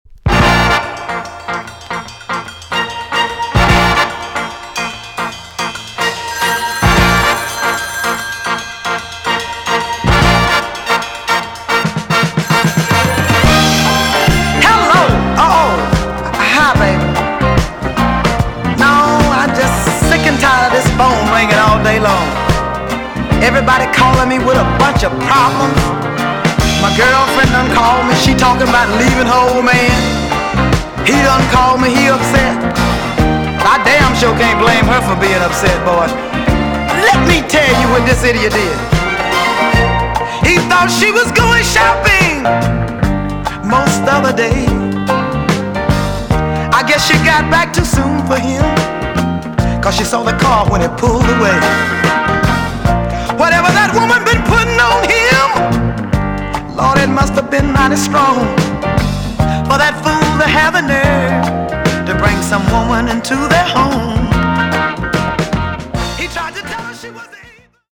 EX- 音はキレイです。
1977 , NICE SOUL TUNE!!